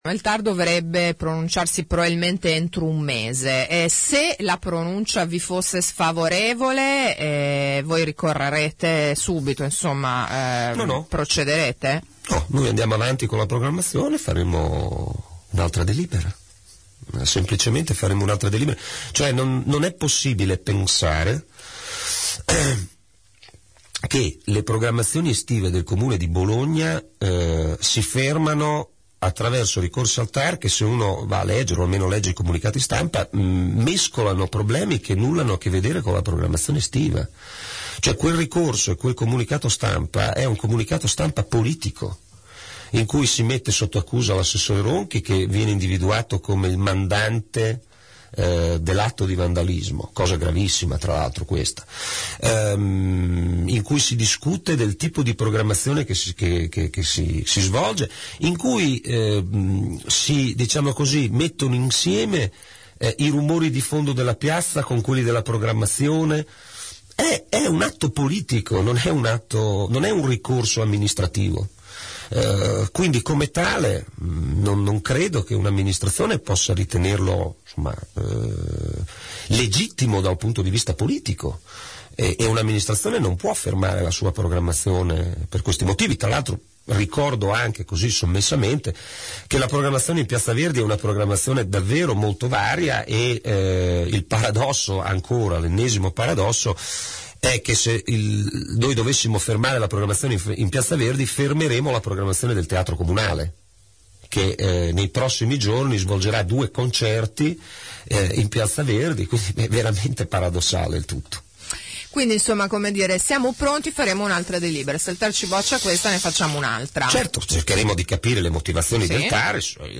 L’ha detto ai nostri microfoni l’assessore alla cultura del Comune di Bologna Alberto Ronchi, ospite ad AngoloB: “Non si può pensare che le programmazioni estive del comune si fermino con ricorsi al Tar che, se uno va a leggere il comunicato stampa, i comitati mescolano problemi che nulla hanno a che vedere con la programmazione estiva”. Per l’assessore quello dei comitati è un “atto politico, non un ricorso amministrativo”.